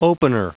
Prononciation du mot opener en anglais (fichier audio)
opener.wav